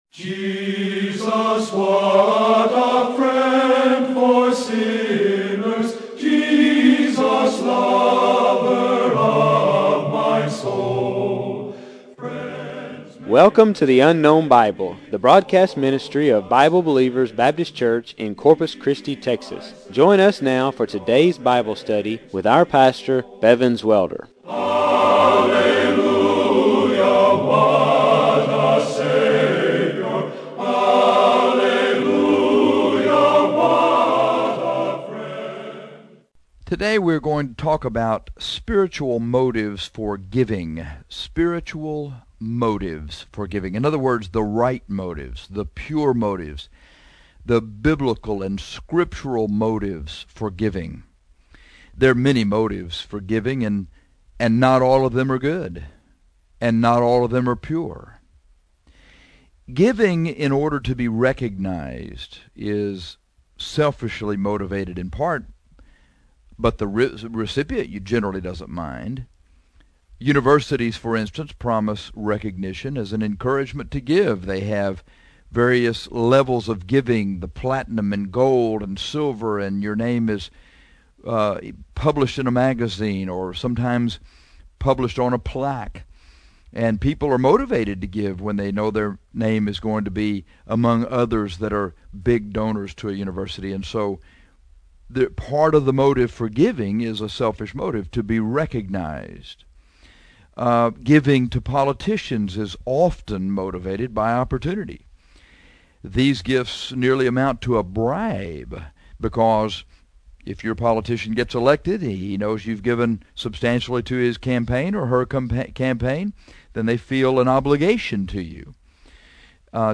But today we are only going to preach on spiritual motives for giving because these motives are pure and right.